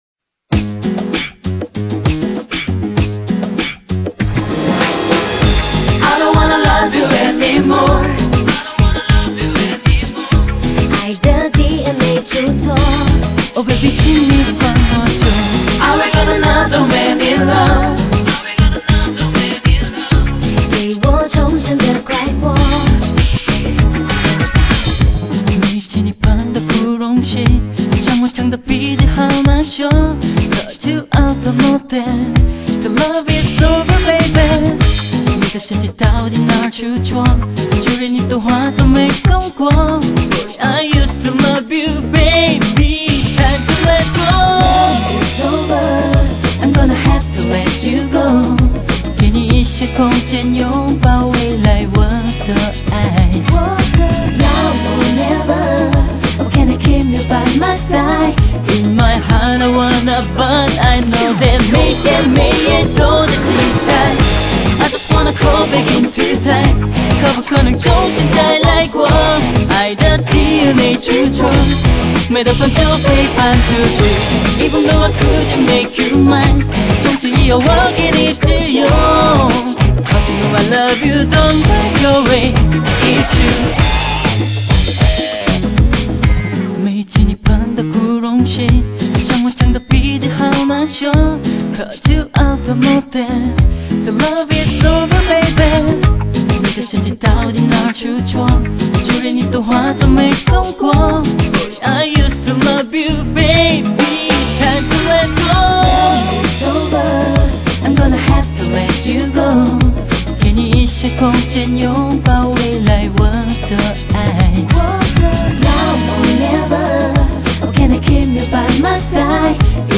音乐风格以纯种美式R&B嘻哈舞曲为主题。
整张专辑包含嬉哈、R＆B曲风。